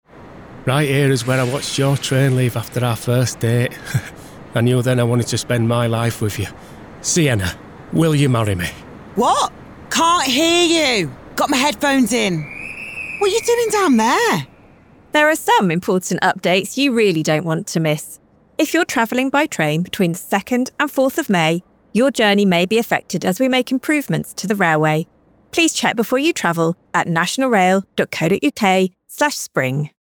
Radio advert